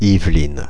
Ääntäminen
Ääntäminen Paris: IPA: [iv.lin] France (Paris): IPA: /iv.lin/ France (Paris): IPA: [lɛ.zi.və.lin] Haettu sana löytyi näillä lähdekielillä: ranska Kieli Käännökset englanti Yvelines Suku: m .